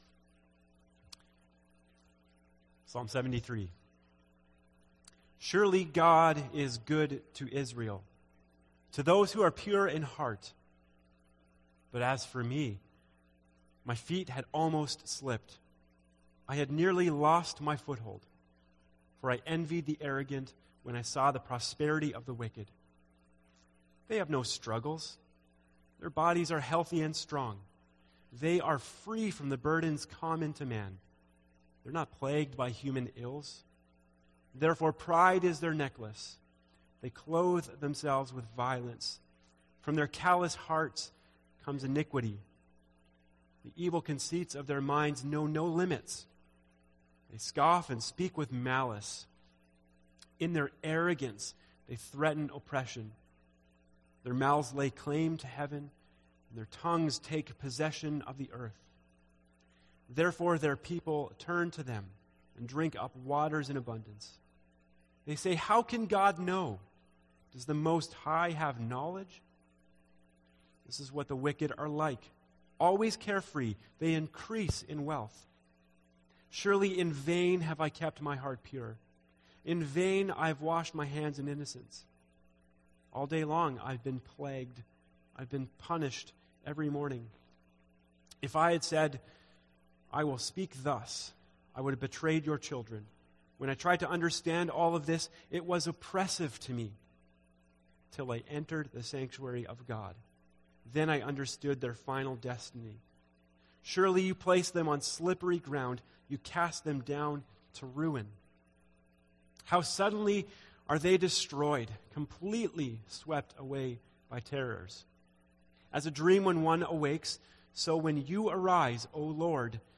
A message from the series "Withness."